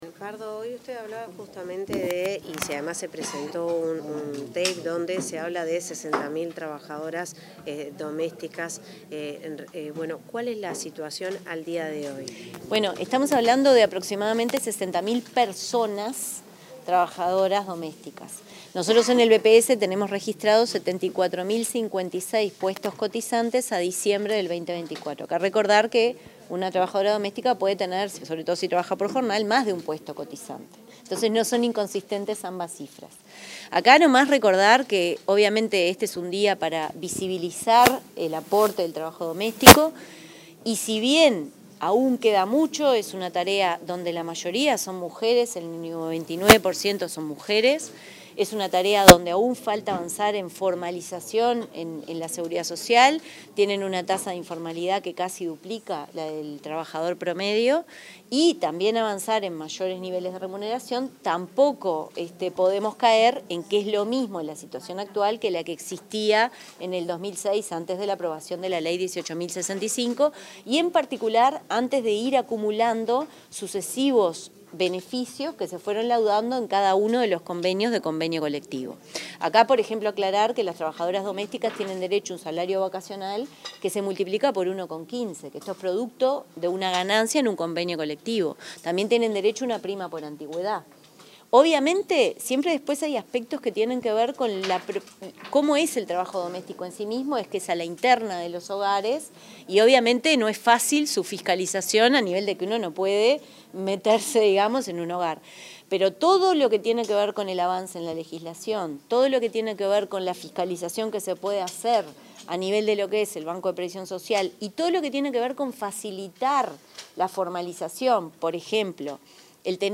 Declaraciones de la presidenta del BPS, Jimena Pardo
Declaraciones de la presidenta del BPS, Jimena Pardo 19/08/2025 Compartir Facebook X Copiar enlace WhatsApp LinkedIn Tras participar en la celebración del Día de la Trabajadora Doméstica, la presidenta del Banco de Previsión Social (BPS), Jimena Pardo, dialogó con la prensa.